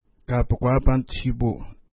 Pronunciation: ka:pukwa:iəpa:nts-ʃi:pu:
Pronunciation